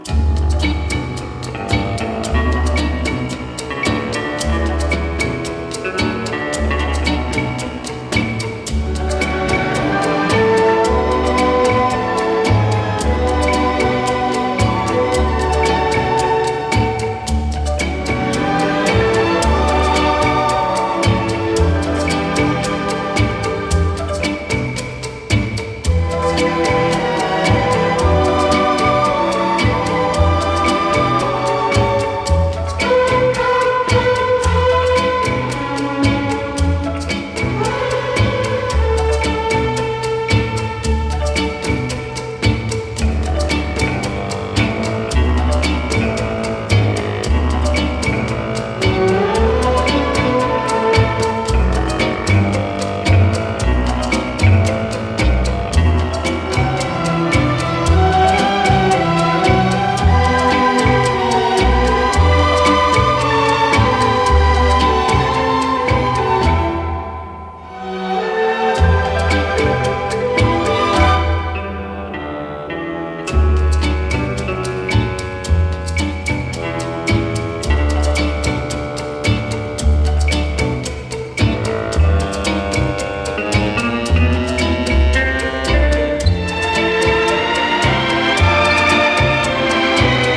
BREAKBEATS/HOUSE / BREAKBEATS / LATIN LOUNGE / JUNGLE
架空のスパイ映画を題材にした妖艶な無国籍ラウンジ・ビーツ！